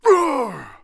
fall_2.wav